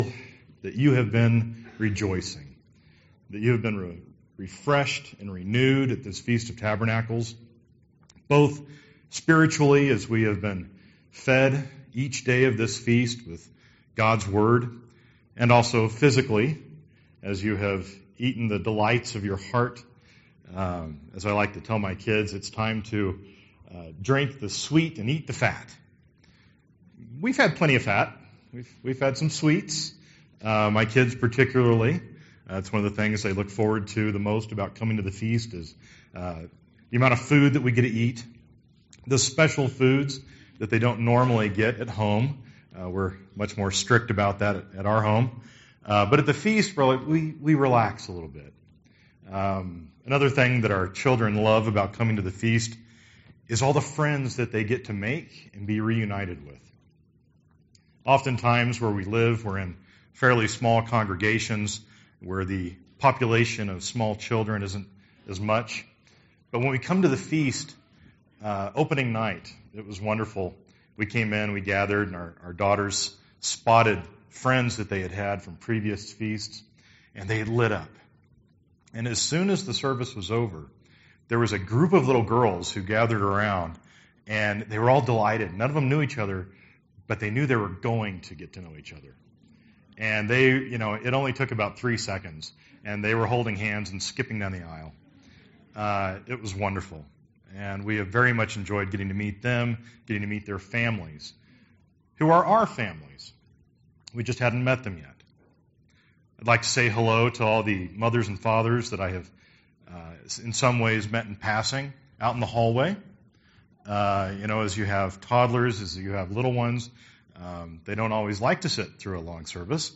This sermon was given at the Steamboat Springs, Colorado 2017 Feast site.